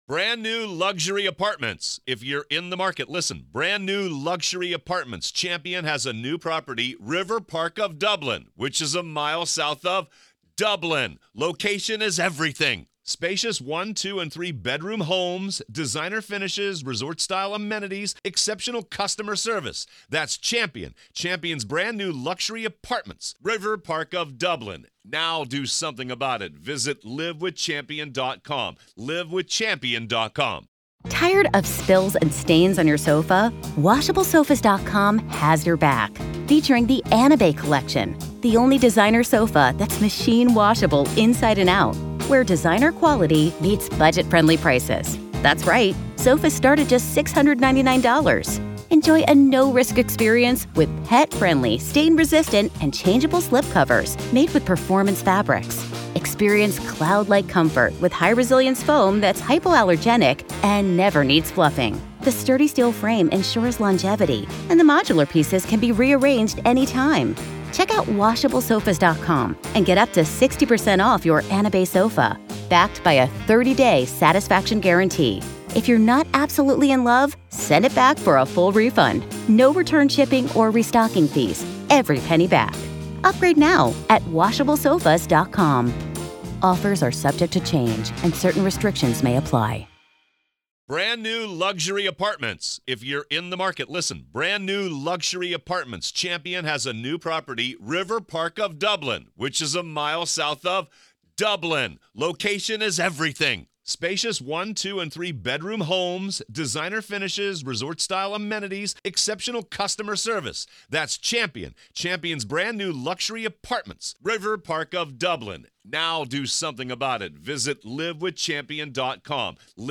This gripping podcast series transports you to the heart of the legal proceedings, providing exclusive access to the in-court audio as the prosecution and defense lay out their arguments, witnesses testify, and emotions run high.